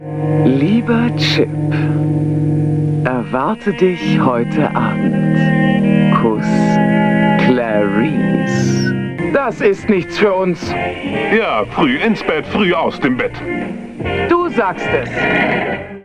Hier sind ein paar Samples aus frühen SuperRTL-Aufzeichnungen.
Aber in Two Chips and a Miss wurden die beiden Nager von zwei Männern eingesprochen.